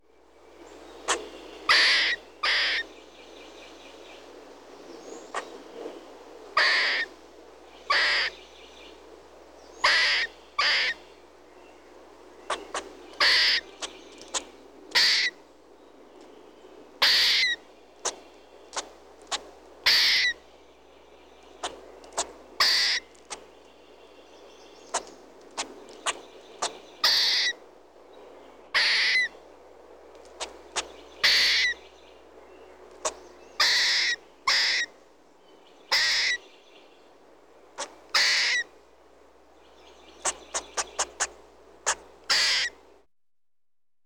Squirrel Chirping Sound
animal
Squirrel Chirping